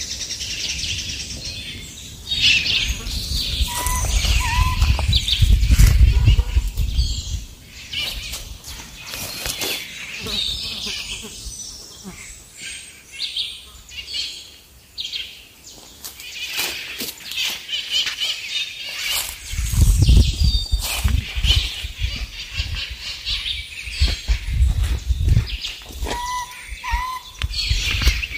Grallaria albigula
Se puede oír en el segundo 3 y 25
Nome em Inglês: White-throated Antpitta
Localidade ou área protegida: Parque Nacional Calilegua
Condição: Selvagem
Certeza: Gravado Vocal